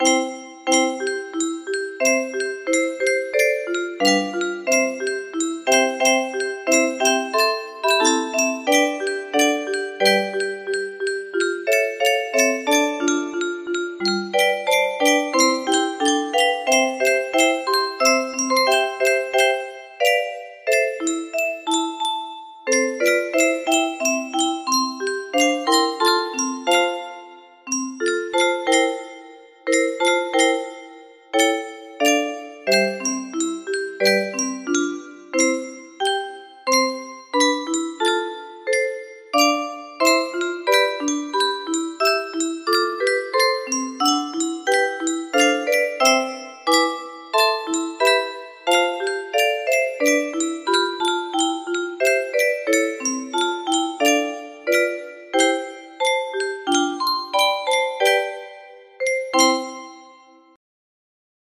Music box arrangement